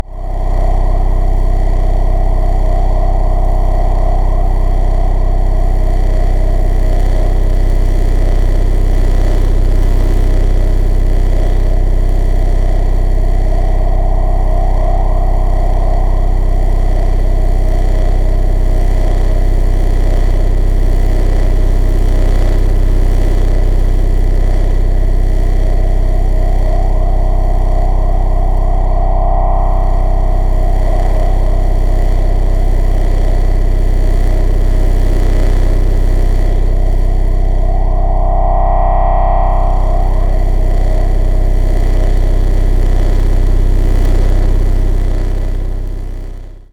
drone4.wav